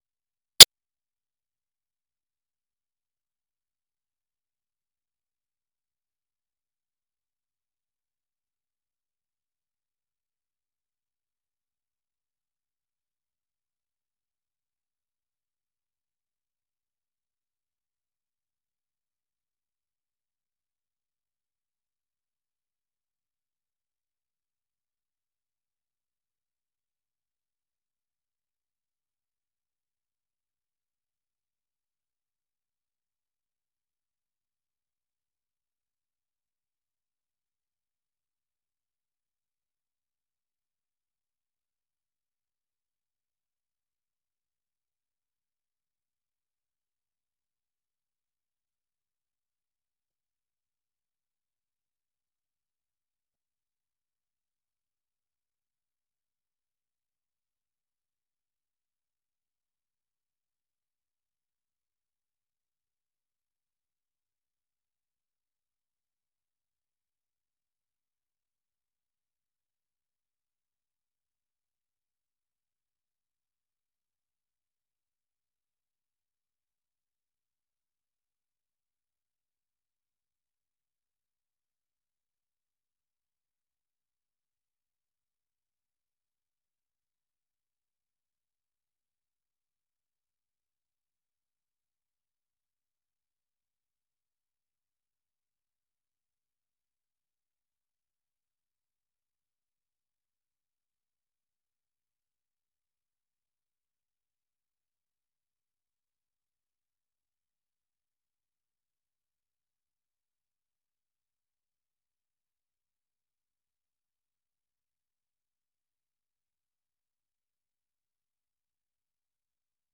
این برنامه به گونۀ زنده از ساعت ۹:۳۰ تا ۱۰:۳۰ شب به وقت افغانستان نشر می‌شود.